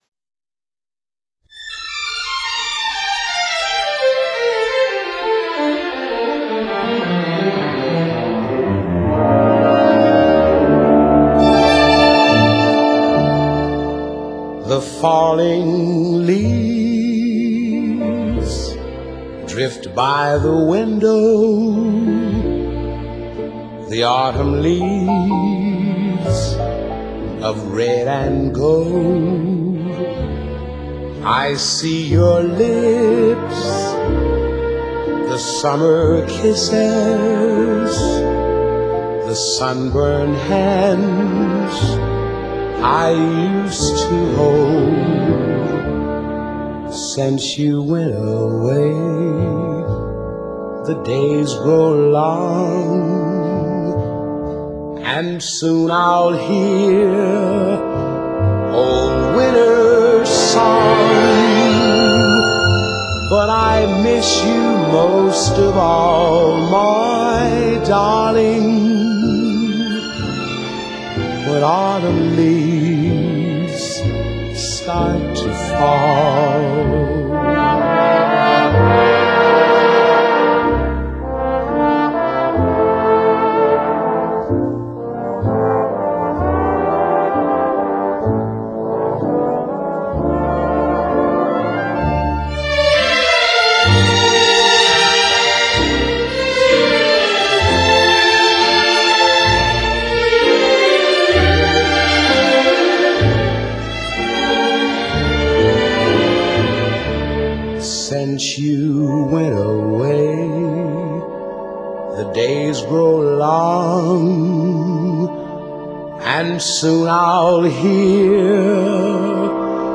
女声版